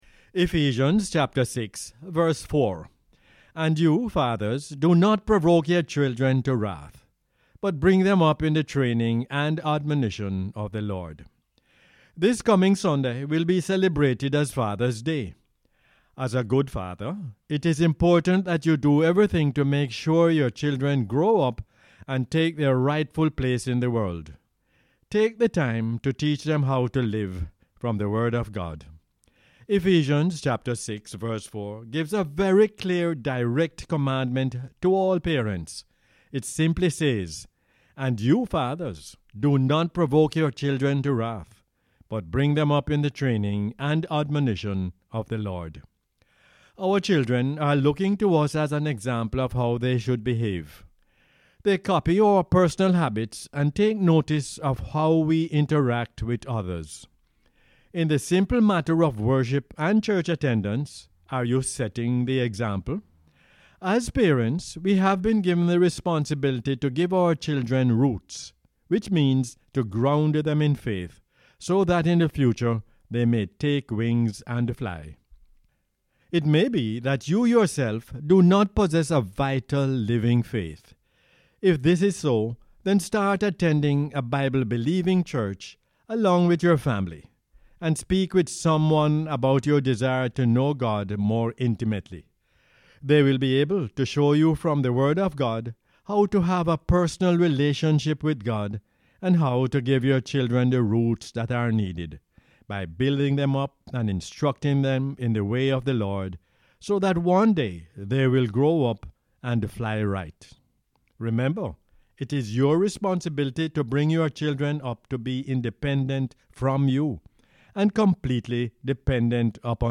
Ephesians 6:4 is the "Word For Jamaica" as aired on the radio on 17 June 2022.